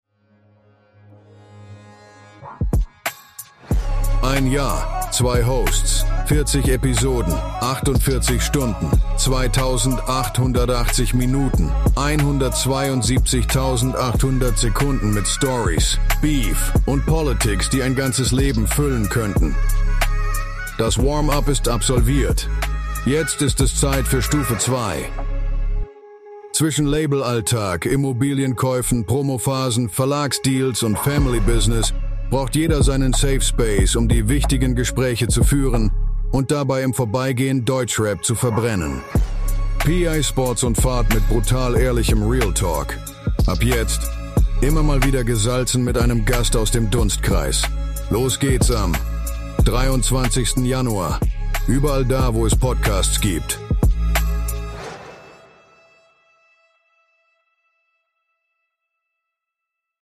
Zwischen Labelalltag, Immobilienkäufen, Promophasen, Verlags-Deals und Family Business braucht jeder seinen Safe Space um die wichtigen Gespräche zu führen und dabei im Vorbeigehen Deutschrap zu verbrennen. PA Sports & Fard mit brutal ehrlichem Real Talk. Immer wieder gewürzt mit einem Gast aus dem Dunstkreis MADE IN GERMANY - Der Podcast von PA Sports & Fard